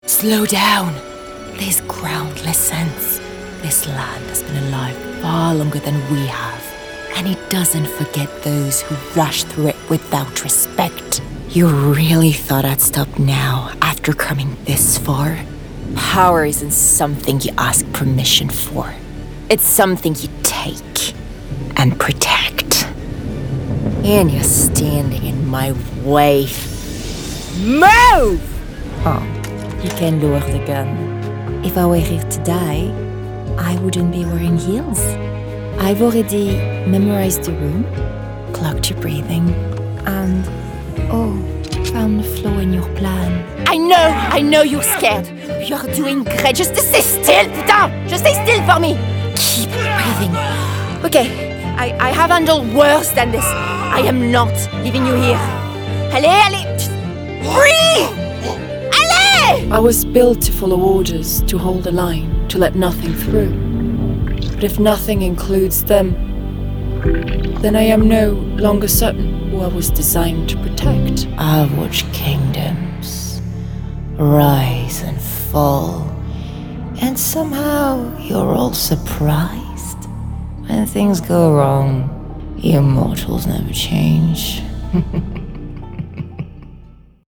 Youthful, Natural, Engaging
Video Game Demo